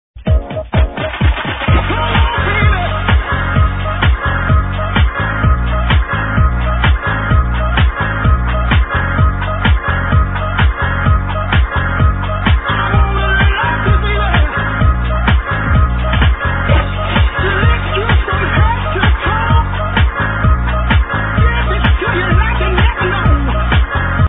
house tune. anyone knows?